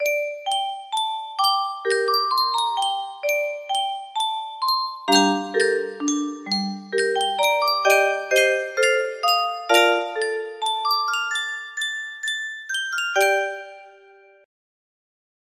IDK music box melody
Grand Illusions 30 (F scale)